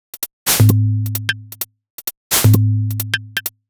Salamanderbrain1 130bpm.wav